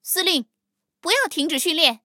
黑豹司令部语音1.OGG